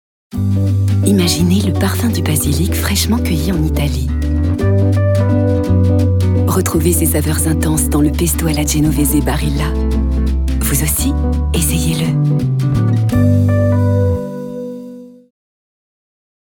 sehr variabel
Mittel minus (25-45)
Commercial (Werbung)